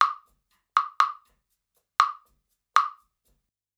Index of /90_sSampleCDs/USB Soundscan vol.36 - Percussion Loops [AKAI] 1CD/Partition A/01-60 CLAVES
60 CLAVE02.wav